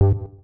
UIClick_Simple Button Synth 01.wav